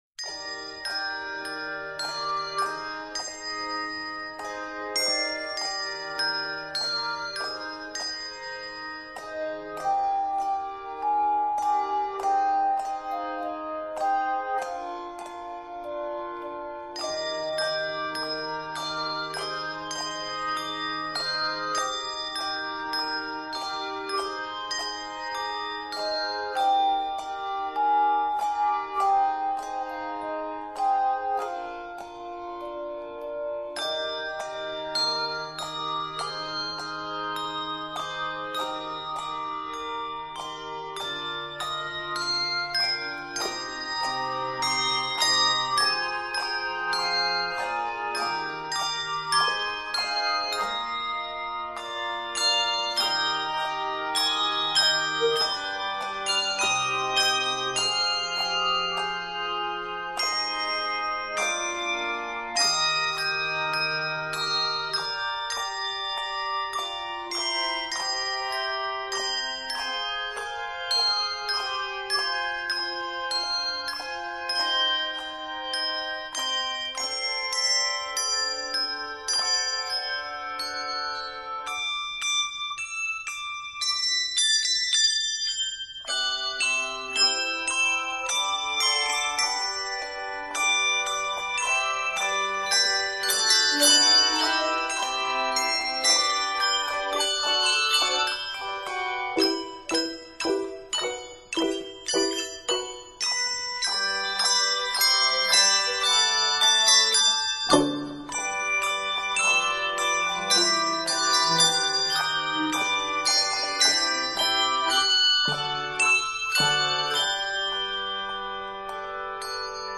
pleasant medley